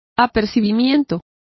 Also find out how apercibimiento is pronounced correctly.